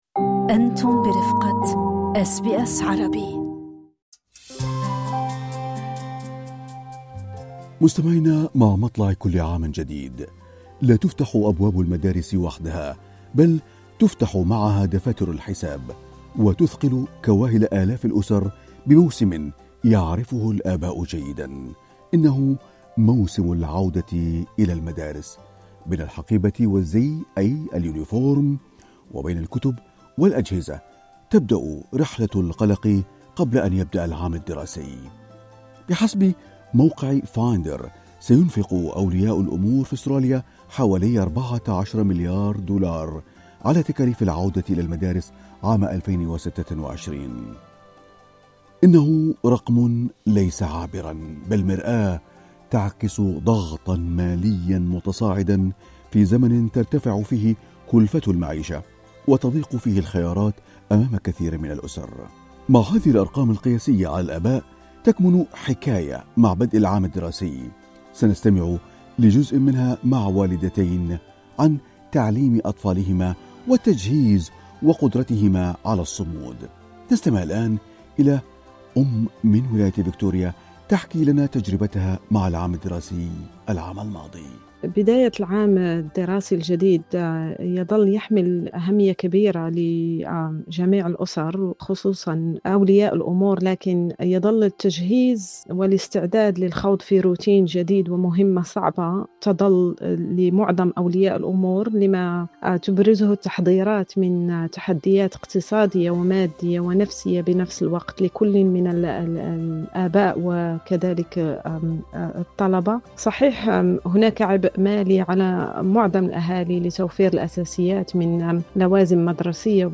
للاستماع لتفاصيل اللقاء، اضغطوا على زر الصوت في الأعلى.